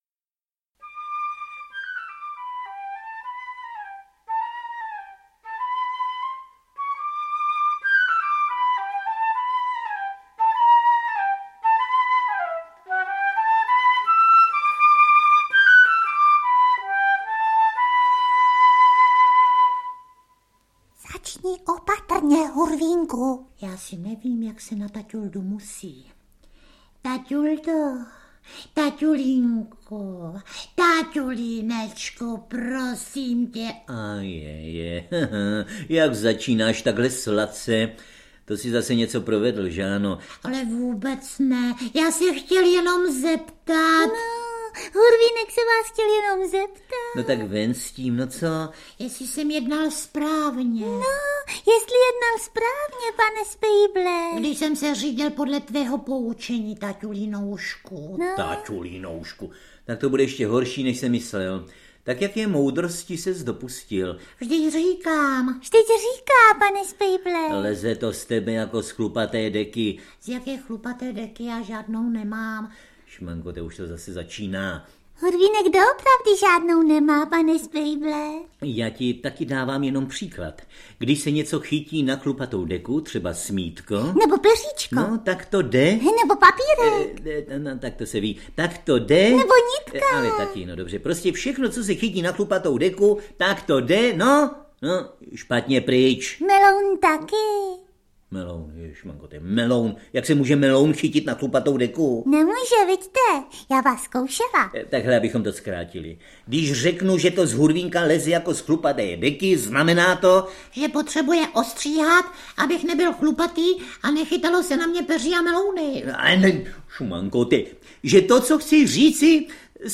Audio kniha
Ukázka z knihy
Audiokniha Hurvínkovy příhody 3 obsahuje několik neznámých Hurvínkových příběhů z rozhlasového archivu (ze sedmdesátých a osmdesátých let), které spojuje autorství Vladimíra Straky, Luboše Homoly, Jiřího Středy.